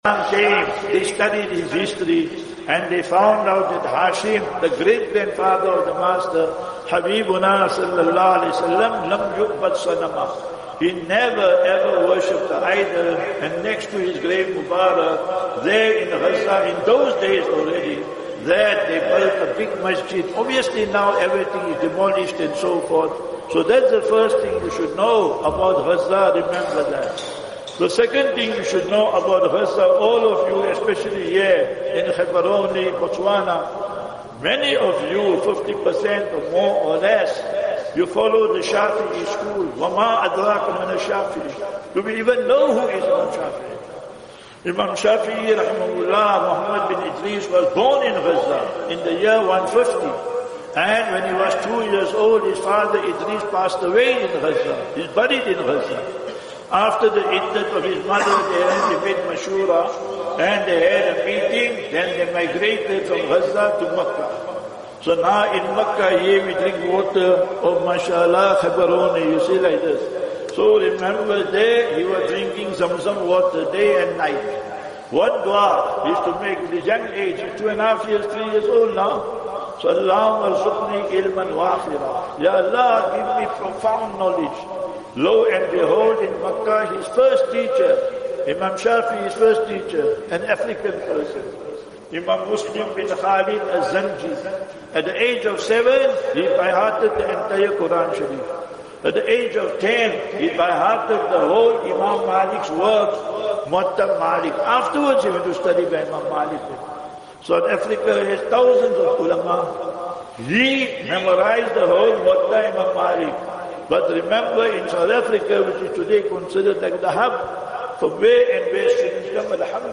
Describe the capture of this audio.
7 Feb 07 Feb 25 - Jumu;ah Lecture - Jaamiah Masjid Gaborone (Botswana)